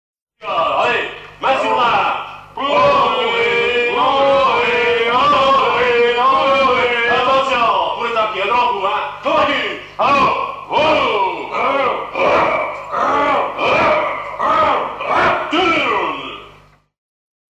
lors d'une mission du musée des Arts et Traditions populaires
à hisser main sur main
Pièce musicale éditée